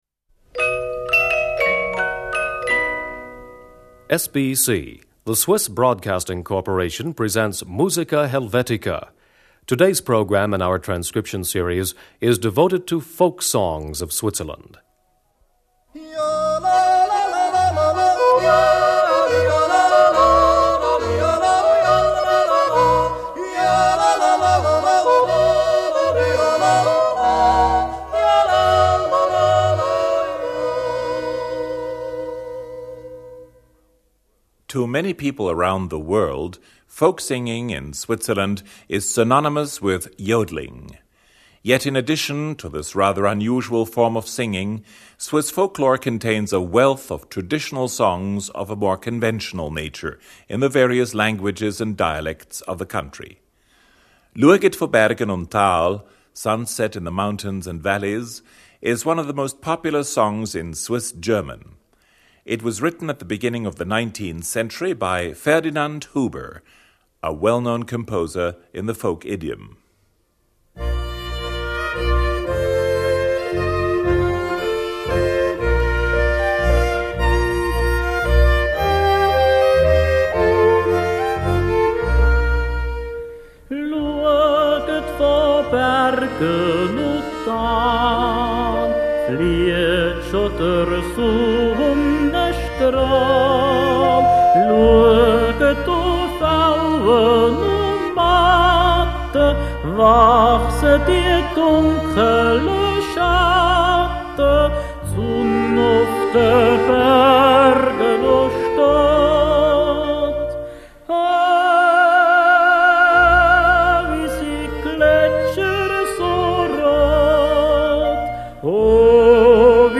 schwyzerörgeli 3.
mixed choir
16th century Swiss mercenaries song
ancient German-Swiss herdsmen’s song